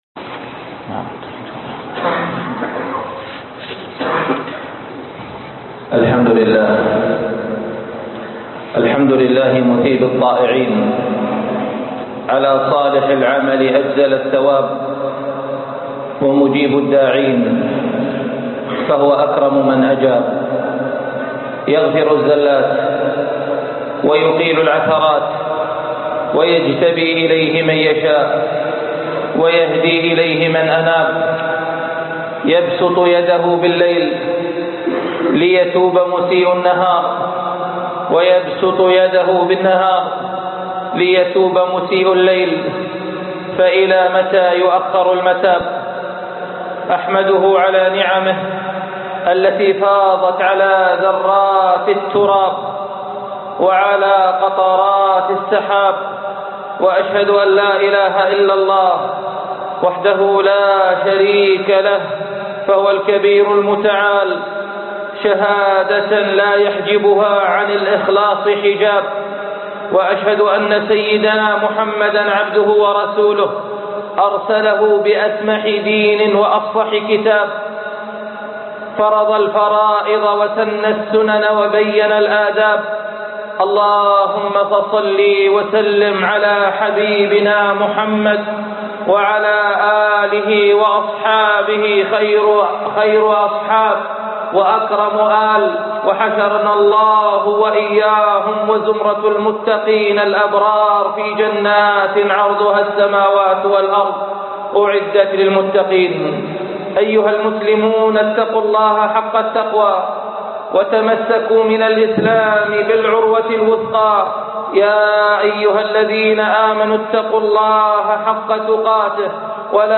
باب الشيطان - خطب الجمعة